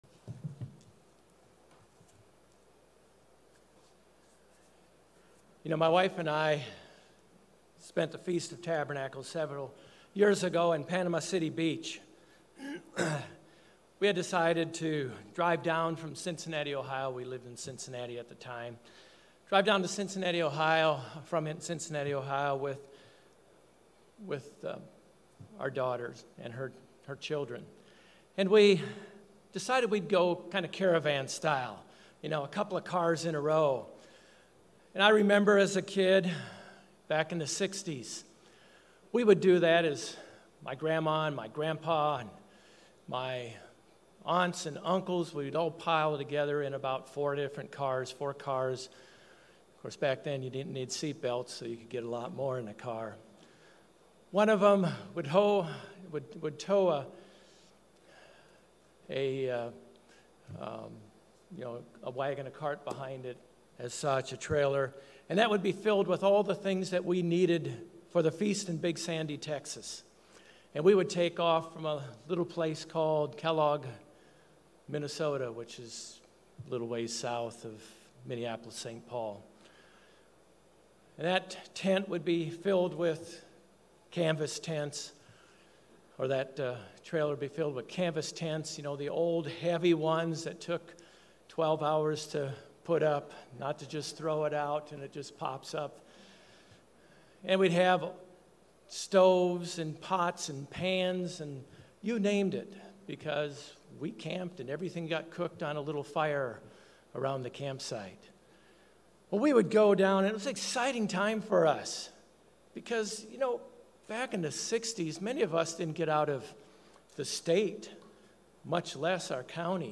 Sermons
Given in Orlando, FL Jacksonville, FL